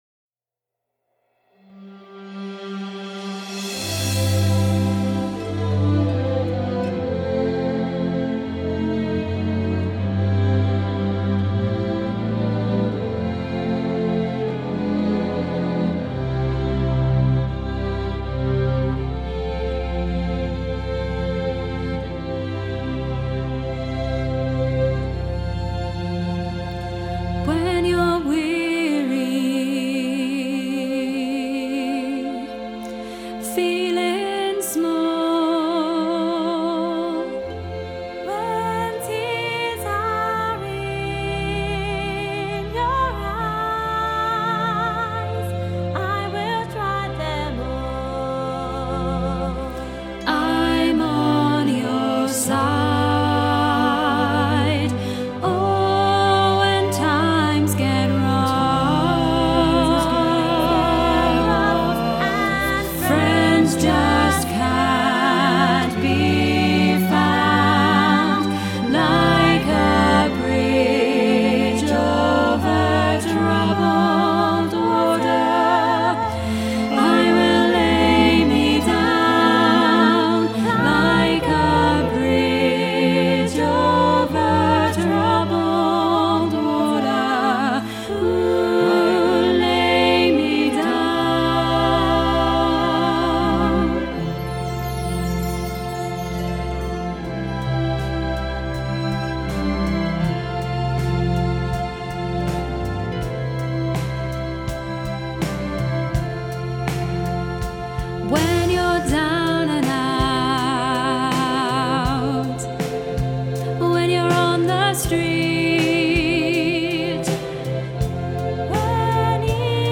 bridge-over-troubled-water-alto-half-mix.mp3